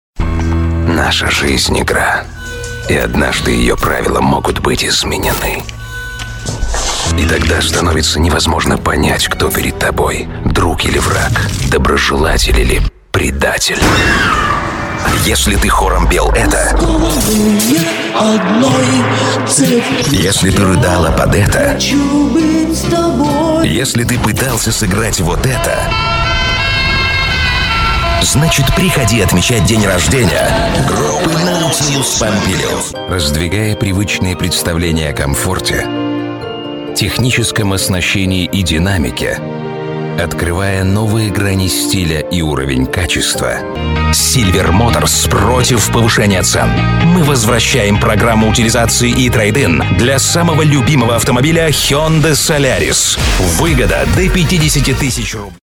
Мощный и колоритный бас-баритон озвучит вашу аудиорекламу или презентацию на высшем уровне.